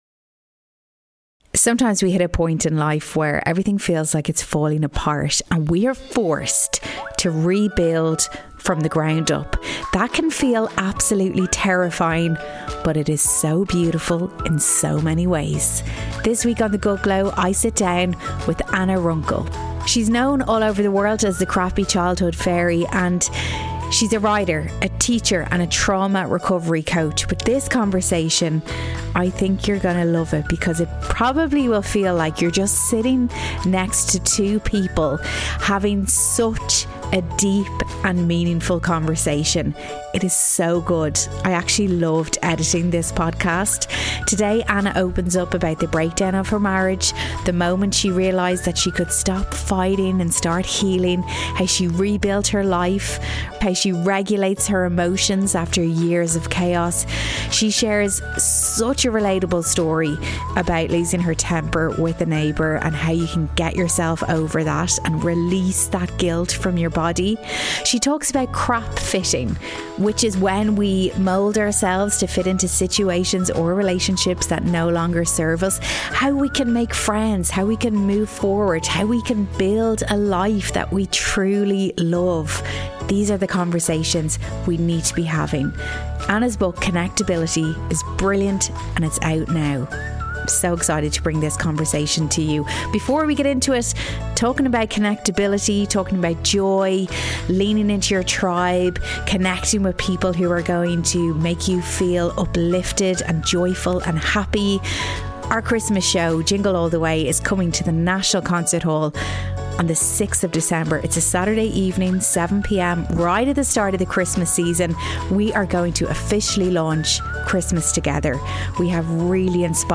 It's such a great conversation, I hope you enjoy it x